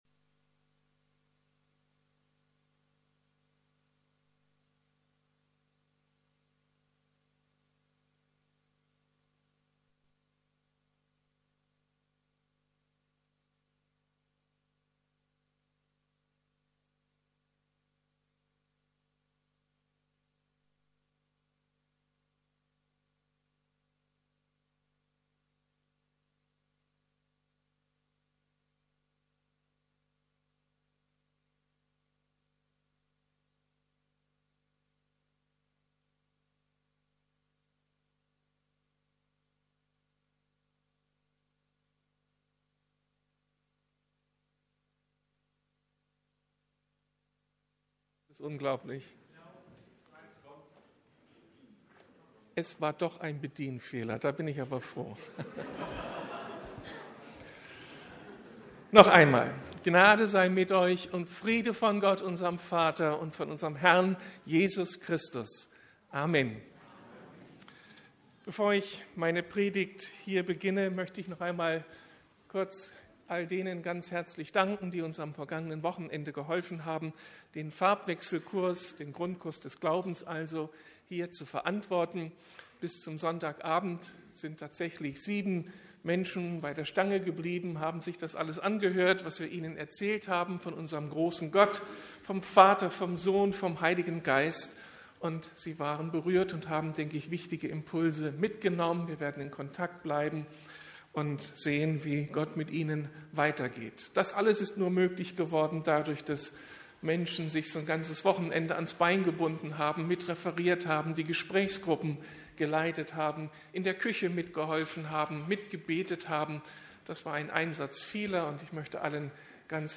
Gott als Mutter entdecken ~ Predigten der LUKAS GEMEINDE Podcast